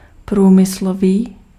Ääntäminen
Ääntäminen France: IPA: [ɛ̃.dys.tʁi.jɛl] Haettu sana löytyi näillä lähdekielillä: ranska Käännös Ääninäyte Adjektiivit 1. průmyslový {m} 2. vtipný {m} Substantiivit 3. průmyslník {m} Suku: m .